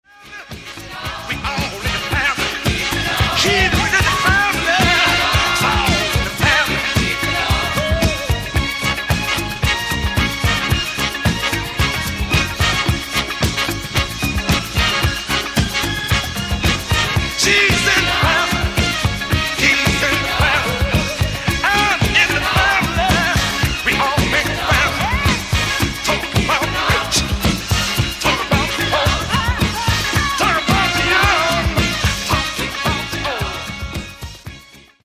Genere: Disco | Soul |